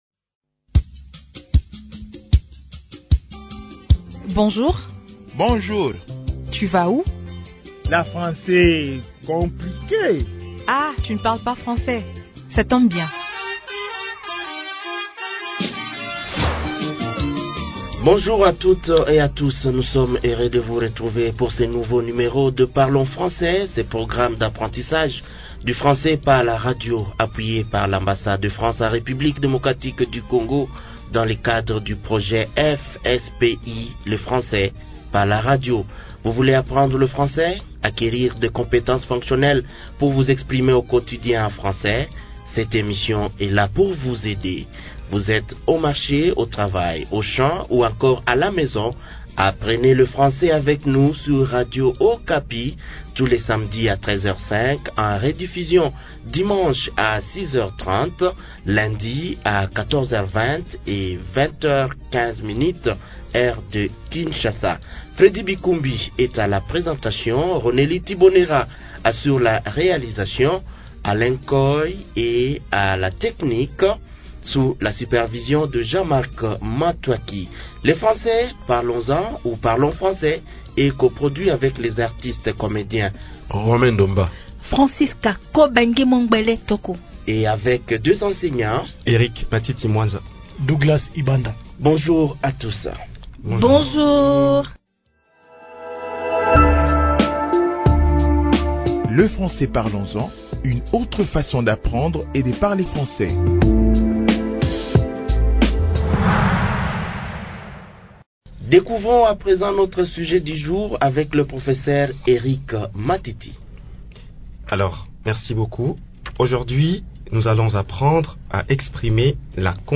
Apprenons, à travers cette nouvelle leçon, les expressions nécessaires pour exprimer une condition. Comme vous le savez, ce programme, soutenu par l’Ambassade de France, est destiné à un public allophone.